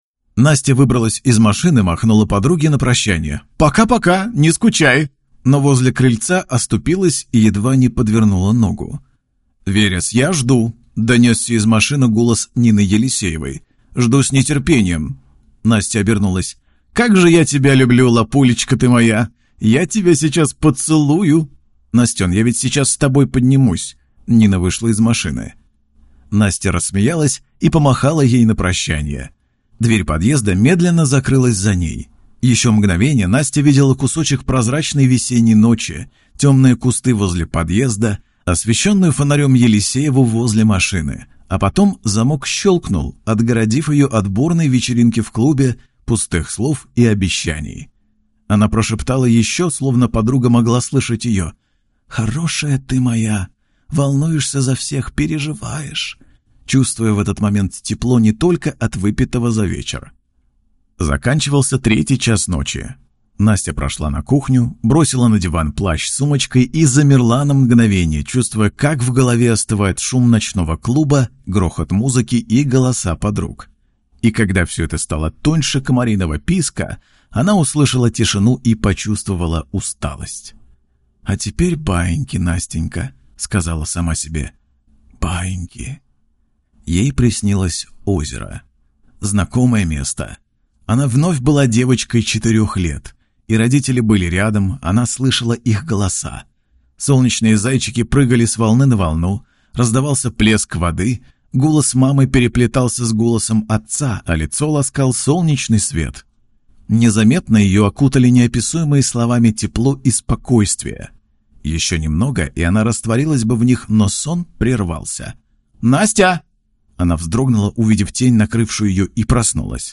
Аудиокнига Солнце Запада | Библиотека аудиокниг